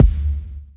stackw_kick.wav